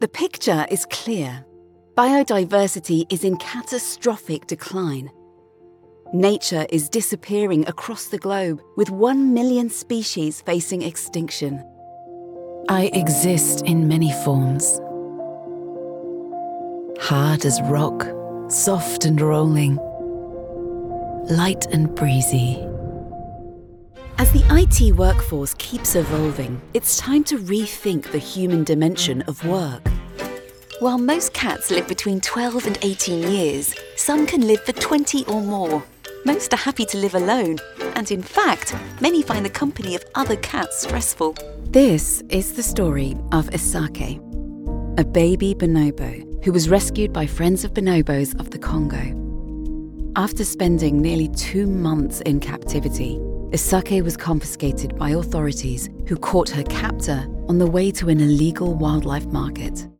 Adult
Has Own Studio